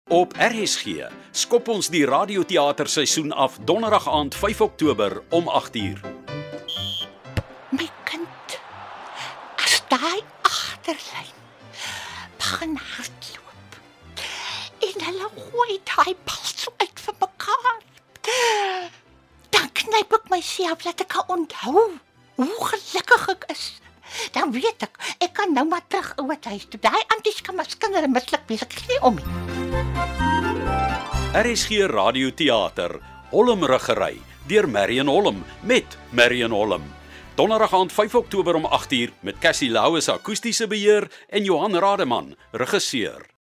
Ons open nuwe radioteater-seisoen met eenvrou-komedie ‘Holmruggery’
promo-radioteater_-holmruggery-01-05-okt.mp3